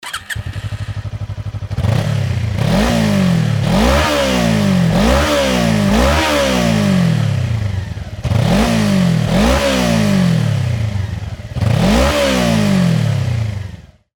という事で排気音を収録してきたのでお聴き下さい。
gsx250r-k&n.mp3